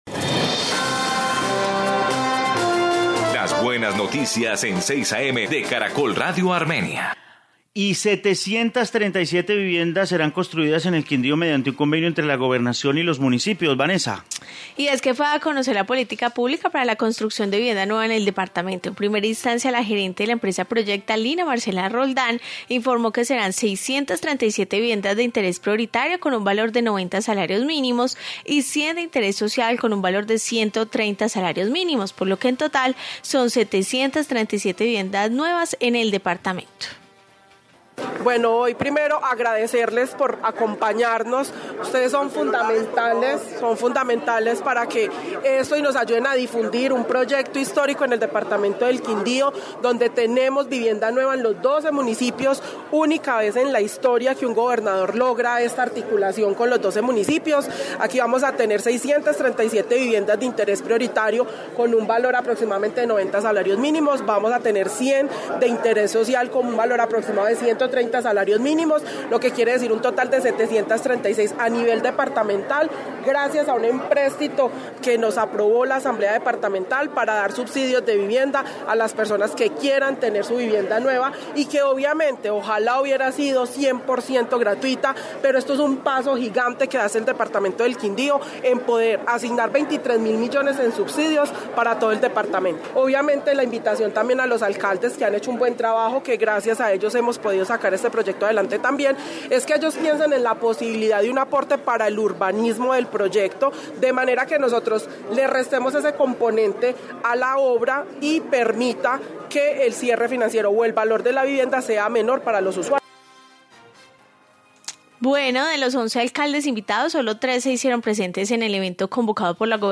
Informe sobre viviendas nuevas